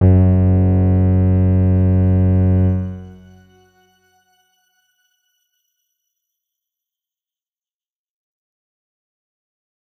X_Grain-F#1-pp.wav